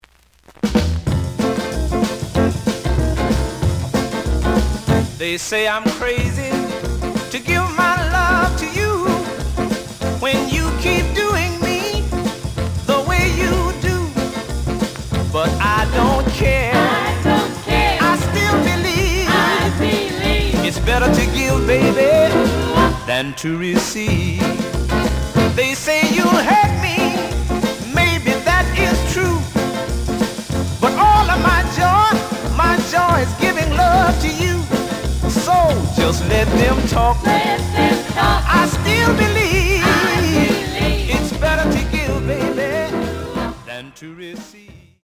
The audio sample is recorded from the actual item.
●Genre: Soul, 60's Soul
Slight noise on beginning of both sides, but almost good.)